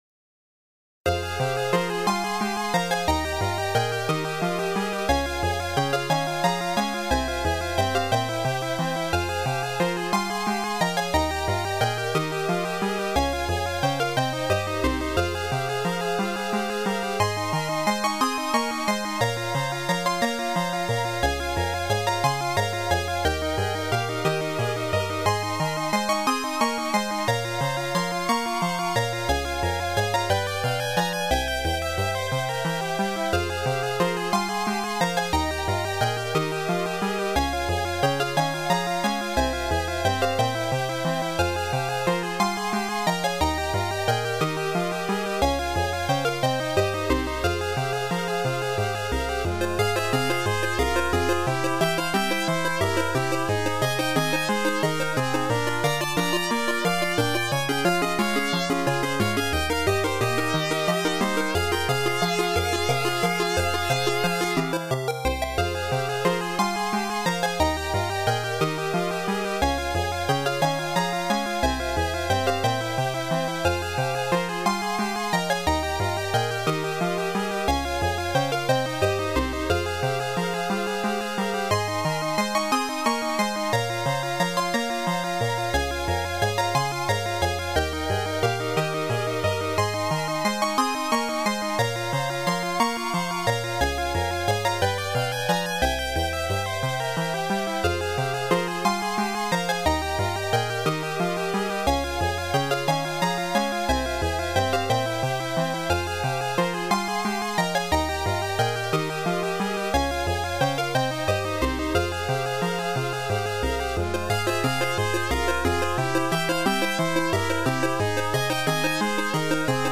ここではMUCOM88winで作ったMUCソースとMP3化したファイルを掲載しています。